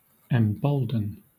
Ääntäminen
IPA : /ɛmˈbəʊld(ə)n/